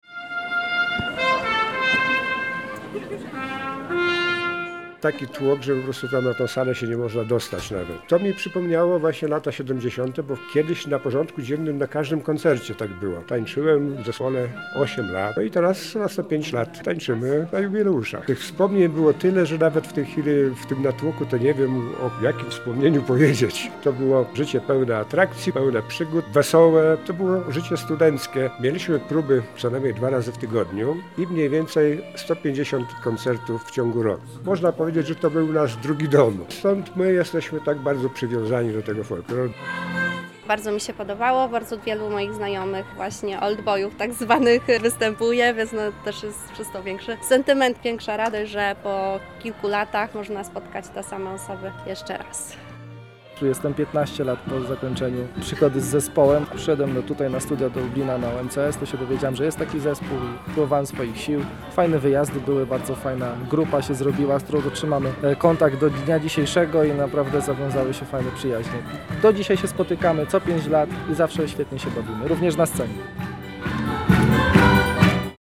Piątkowy koncert jubileuszowy „Byliśmy, jesteśmy, będziemy” przyciągnął do Chatki Żaka tłumy gości – zarówno lokalnych, jak i zagranicznych.